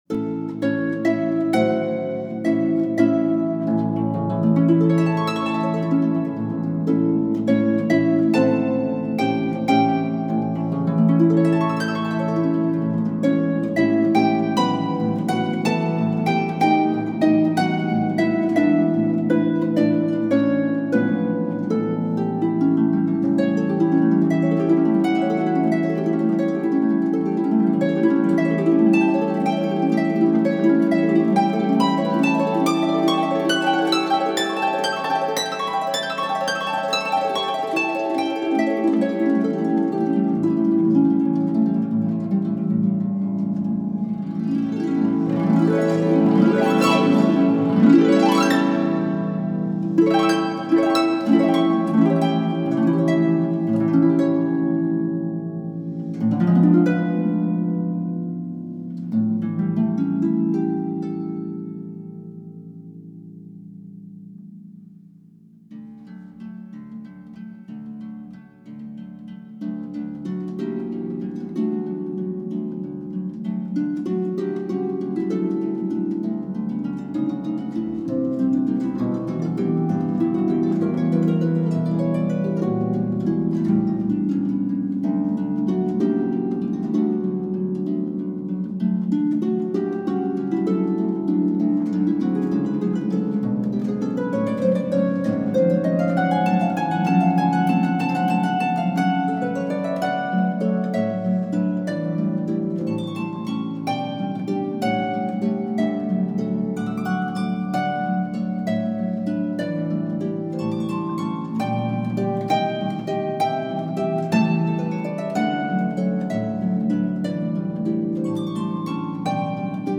arrangement for solo pedal harp